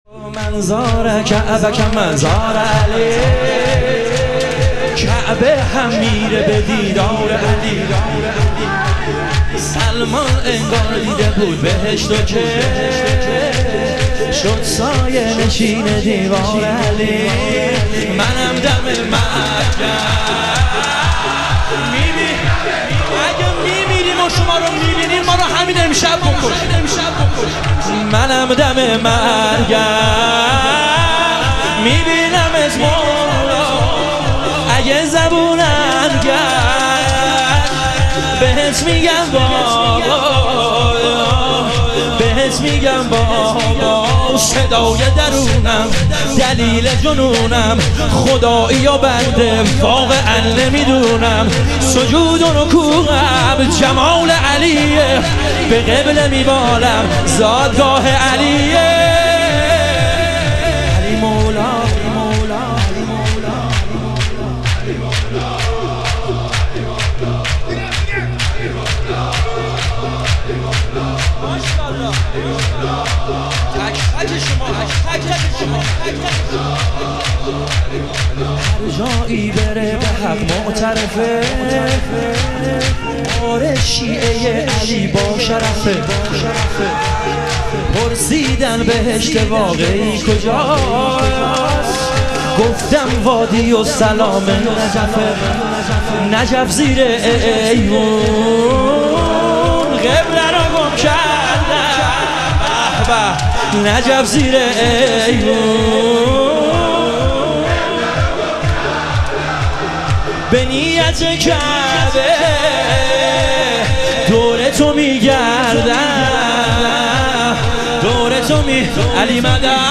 شهادت امام کاظم علیه السلام - شور